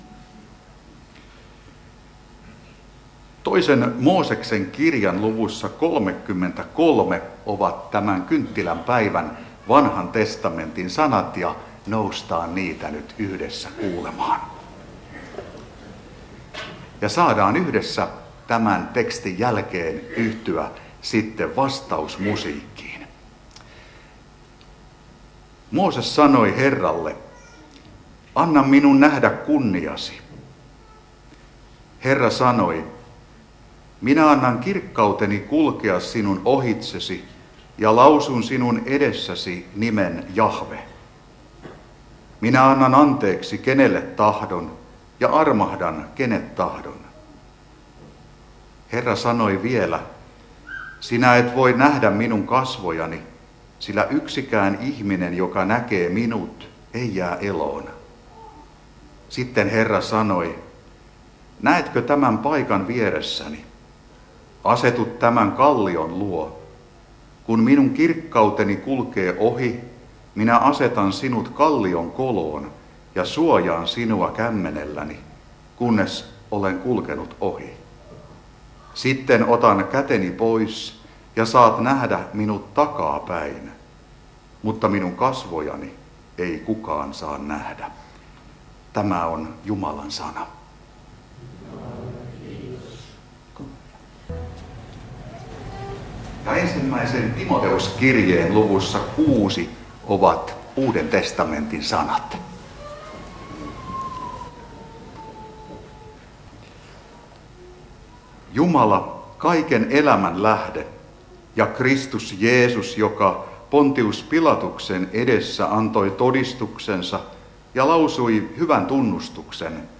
Karkku